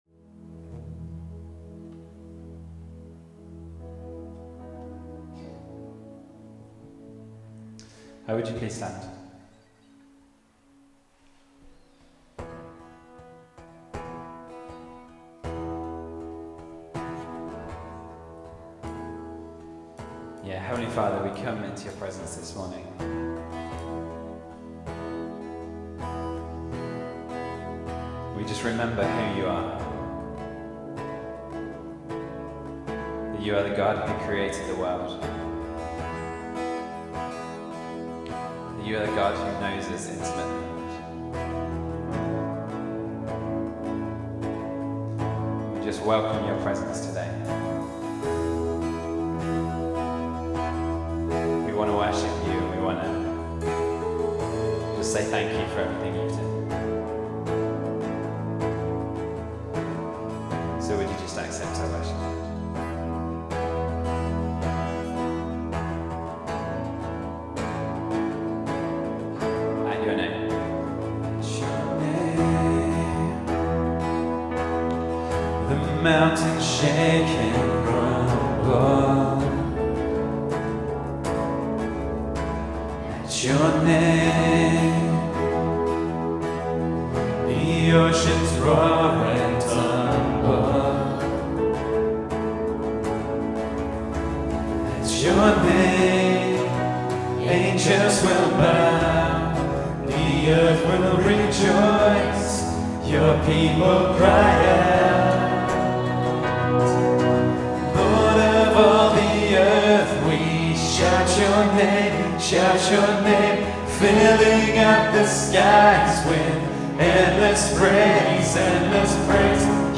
Worship September 13, 2015